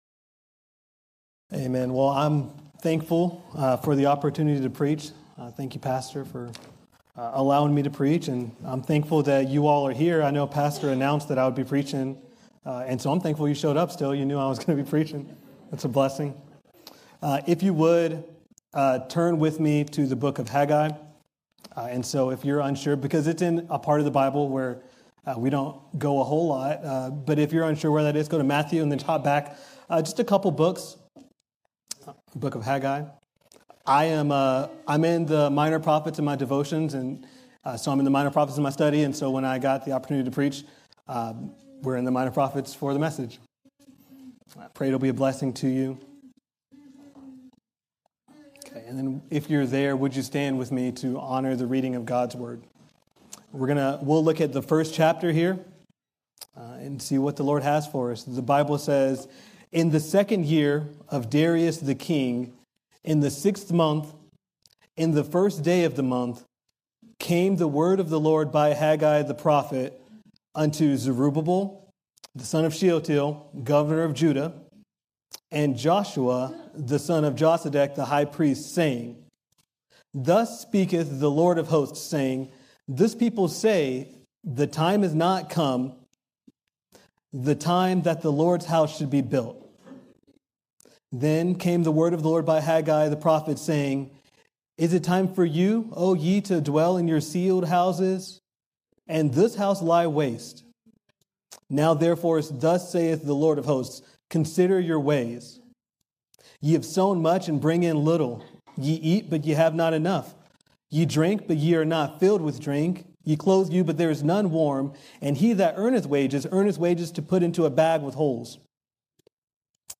A message from the series "The Armor of God."